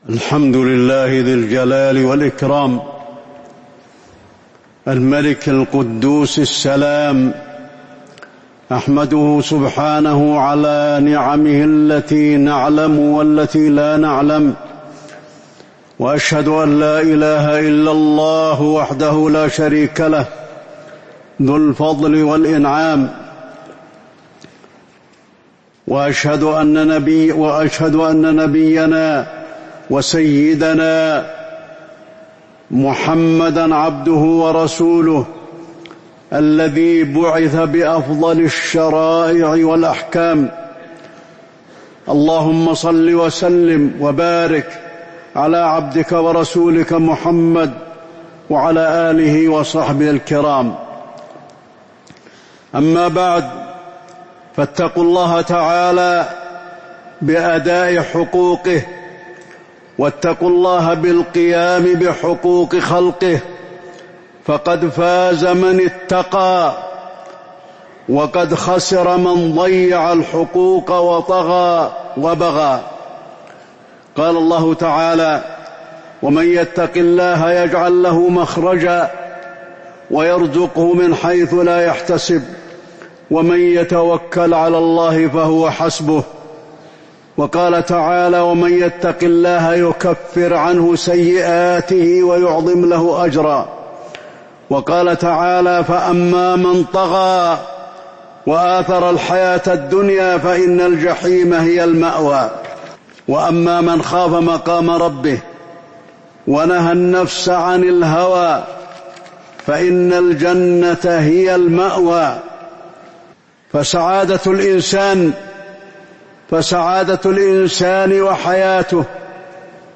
تاريخ النشر ١٠ جمادى الأولى ١٤٤٥ هـ المكان: المسجد النبوي الشيخ: فضيلة الشيخ د. علي بن عبدالرحمن الحذيفي فضيلة الشيخ د. علي بن عبدالرحمن الحذيفي إنما المؤمنون إخوة